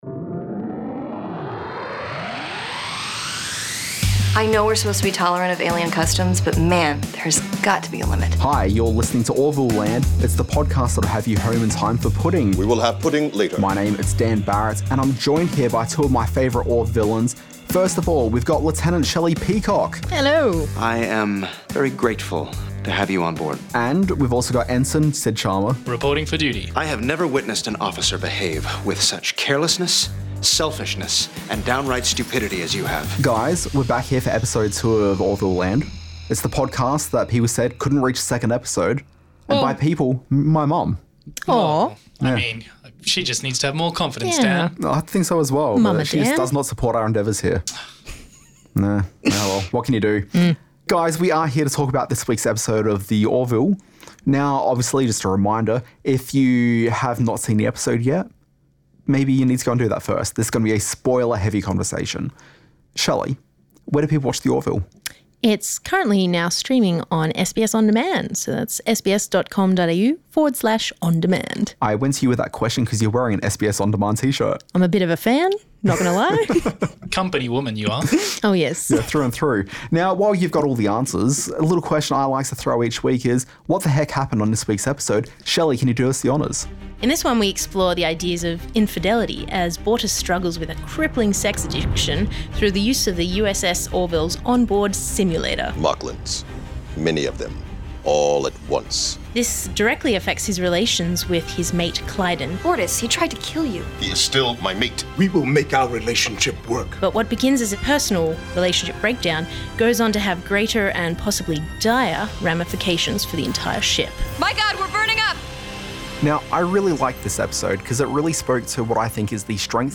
This week on the ORVILLELAND podcast we interview series star Adrianne Palicki, watch ten planets ready to be gobbled up by a super sun, and tackle Bortus' sex addiction as ORVILLELAND tackles Season 2 - Episode 2 "Primal Urges".